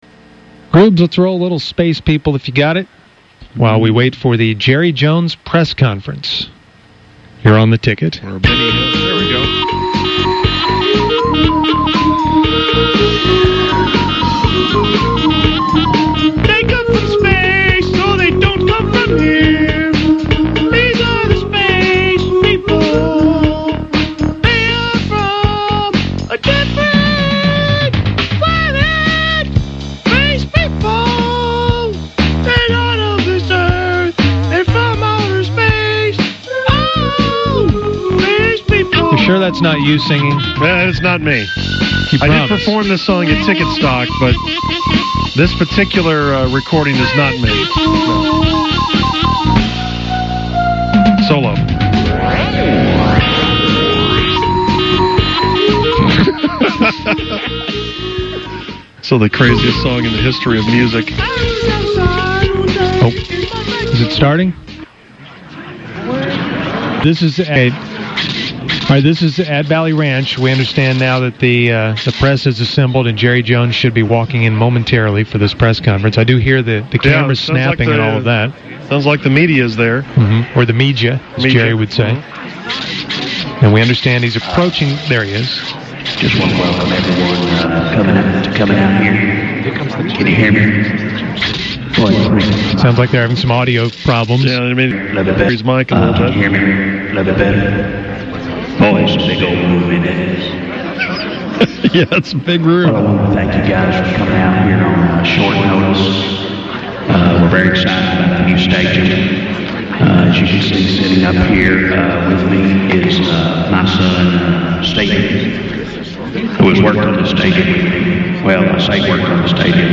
A kitchen that was at the other end of a long tunnel.